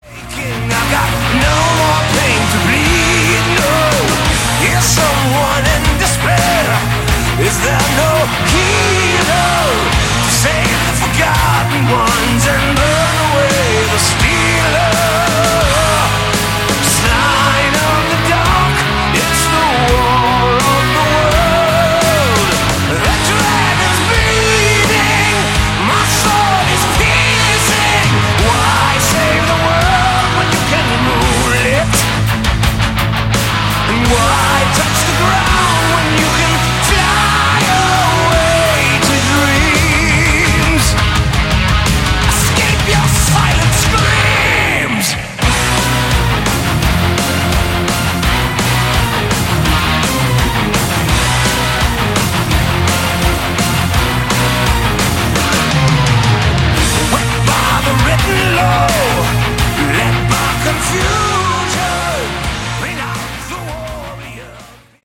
Category: Melodic Metal
vocals
drums
bass
guitars